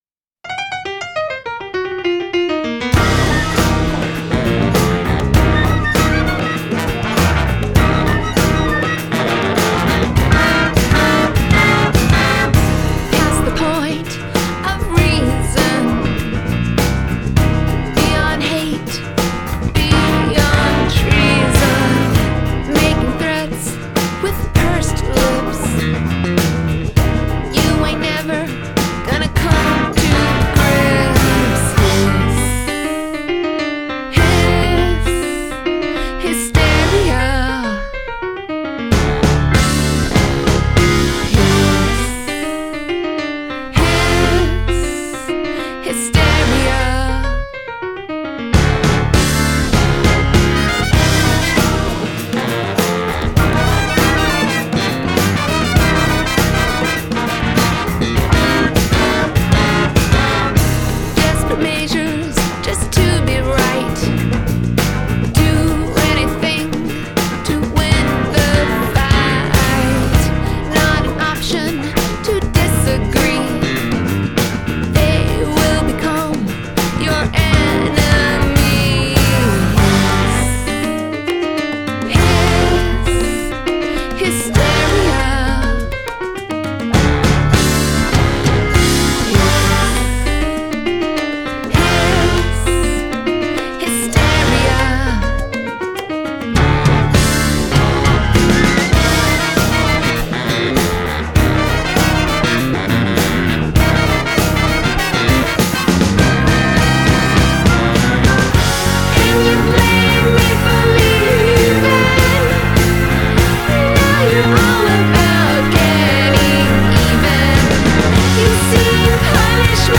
Adult Contemporary , Comedy , Indie Pop , Musical Theatre
Soft Rock